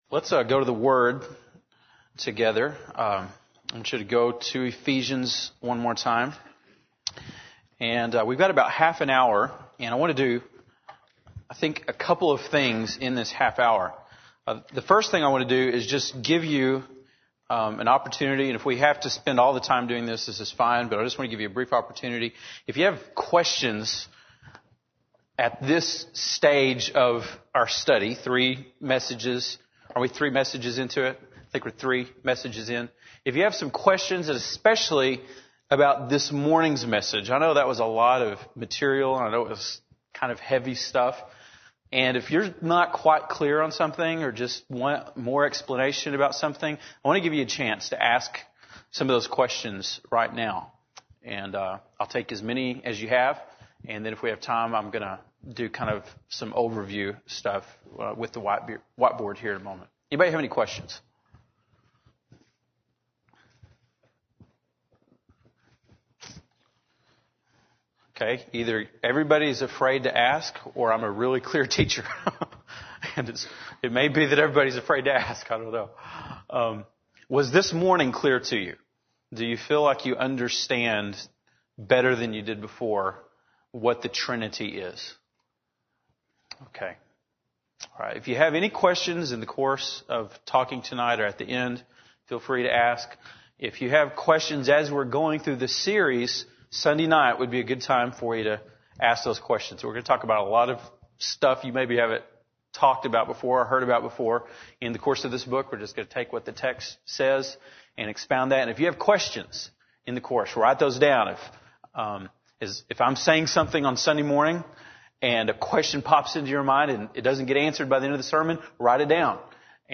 August 8, 2004 (Sunday Evening)